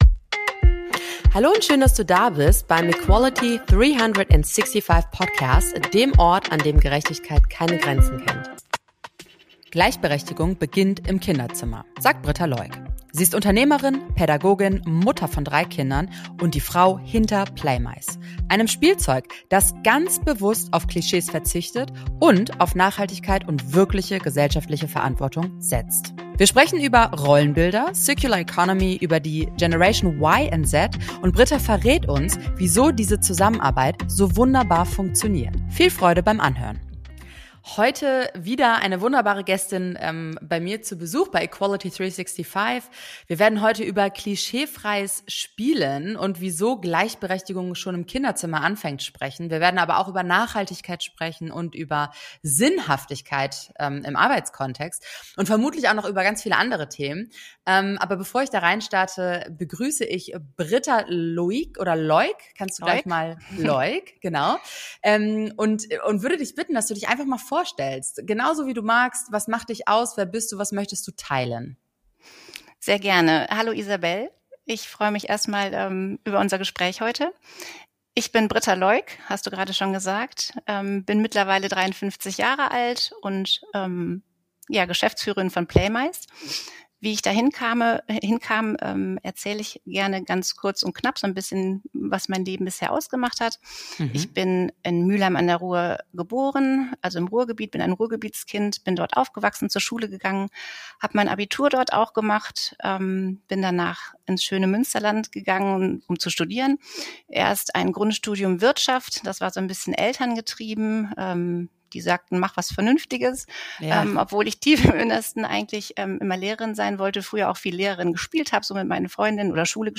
Ein Gespräch über Wertschöpfung, Verantwortung – und leise Revolutionen im Kinderzimmer.